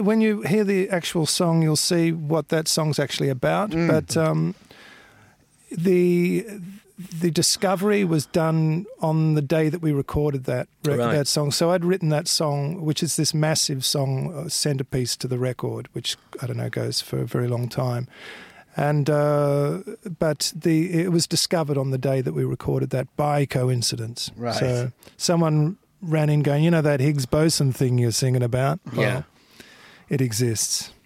Nick Cave chats to Radcliffe and Maconie about his new album Push The Sky Away and the weird coincidences on how the song Higgs Boson Blues came about.